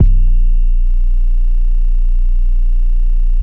{808} lose.wav